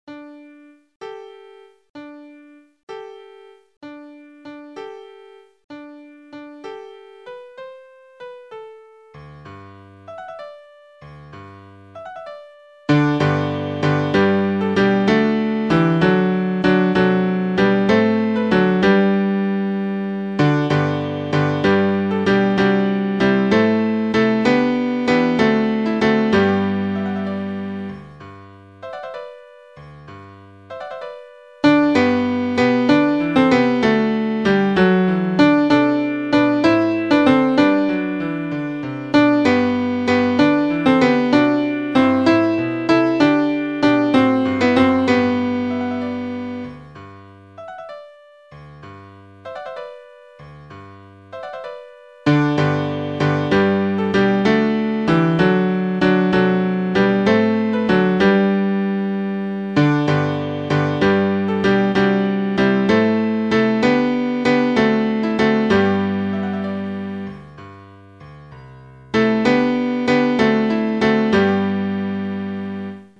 I am So Glad Each Christmas Eve (Knudsen)     Your part emphasized:     Soprano     Alto
Tenor    Bass